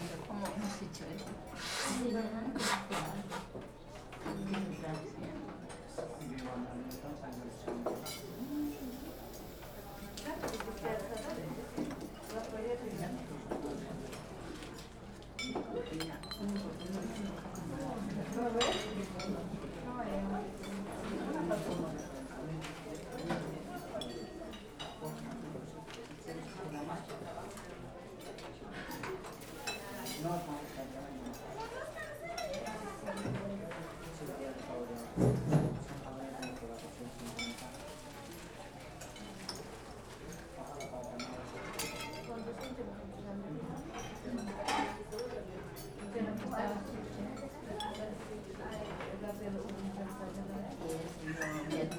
Sakura Melody - Restaurant Ambient Sounds
What type of restaurant do you have in your mind while hearing this? :)
Restaurant_Ambience.wav (d)